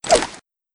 grenade_toss.wav